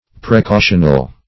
Precautional \Pre*cau"tion*al\, a.